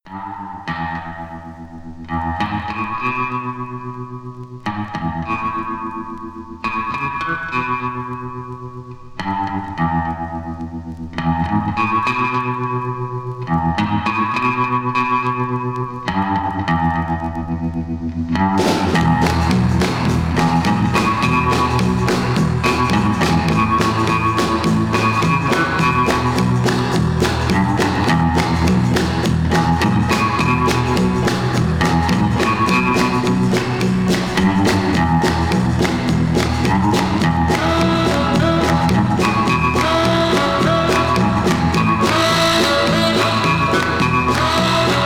R&B, R&R, Jazz, Rock, Surf　USA　12inchレコード　33rpm　Stereo